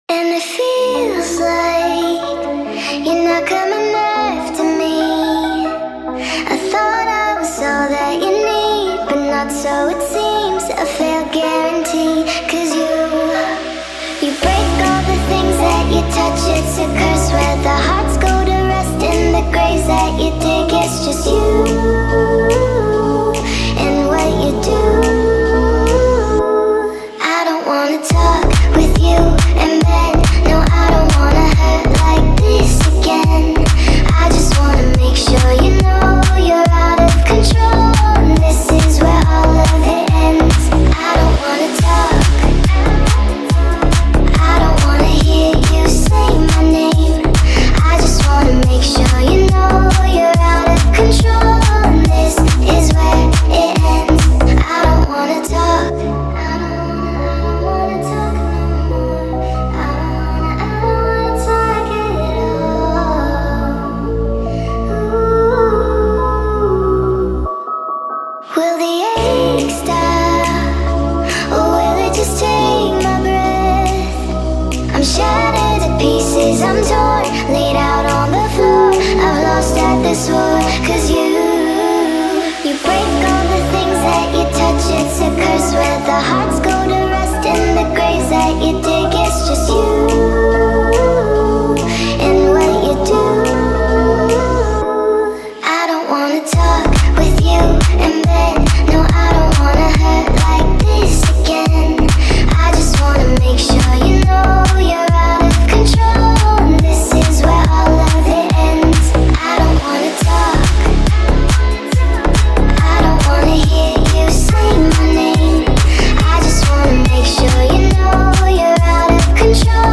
version nightcore